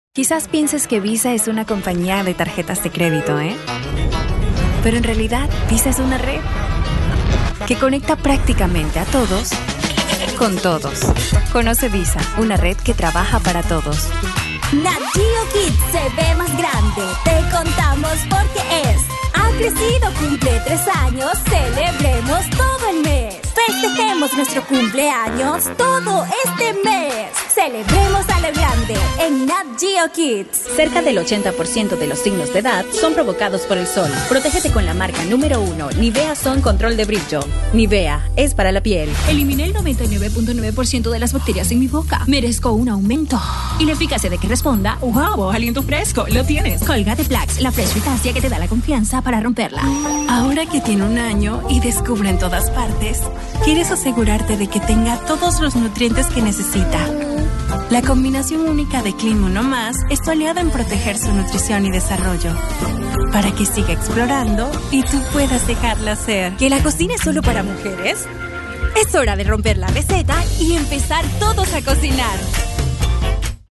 Desde el 2012 es locutora comercial prestando su voz para marcas nacionales e internacionales.